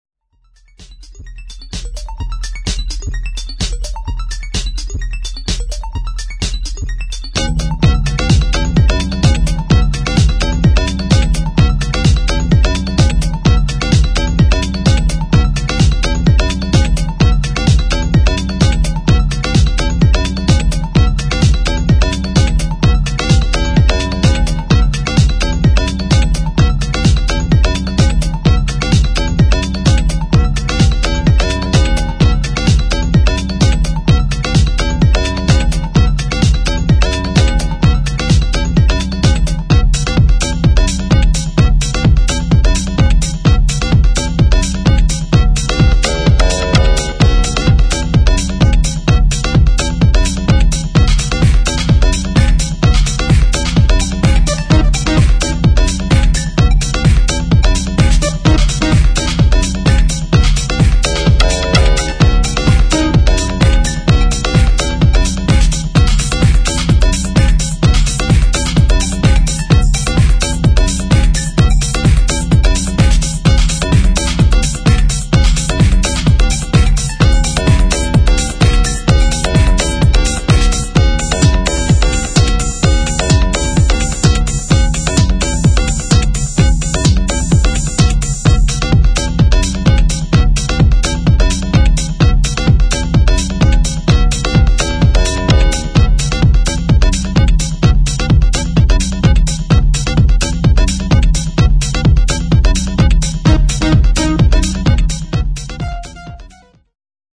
[ TECHNO / ELECTRO ]